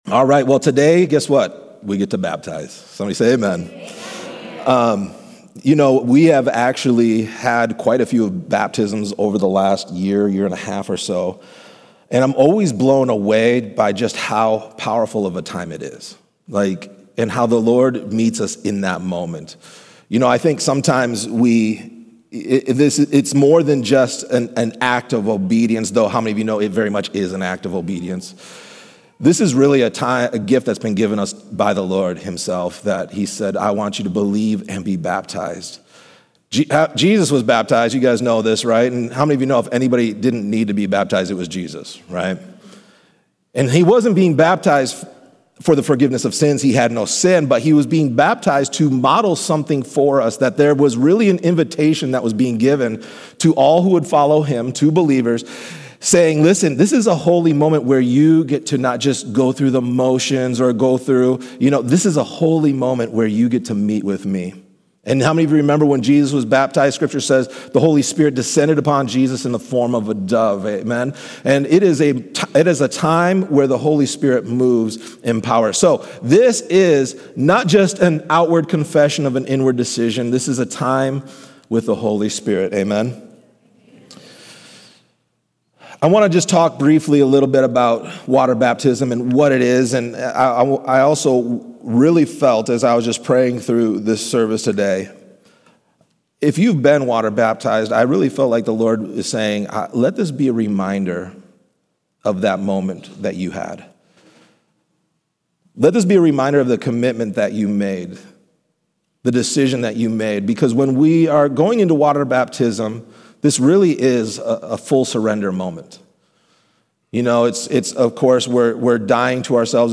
This sermon calls believers back to the simplicity of following Jesus with surrendered hearts.